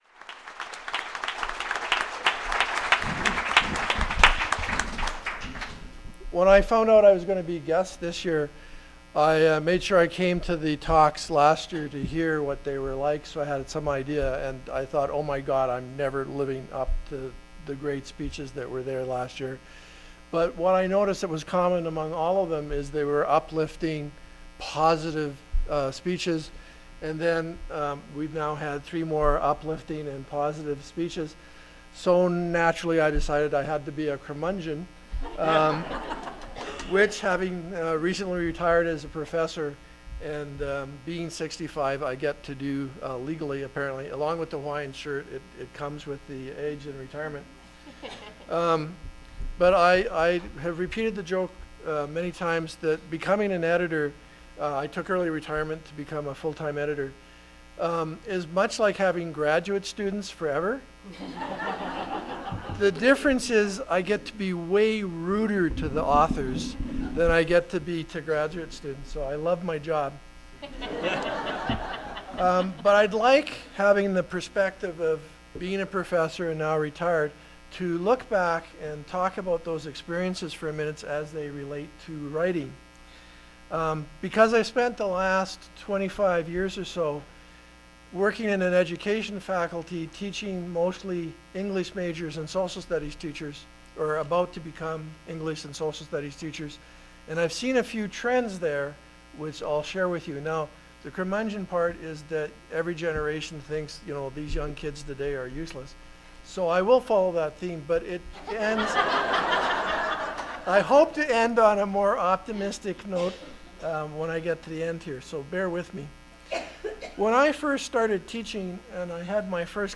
Keynote Addresses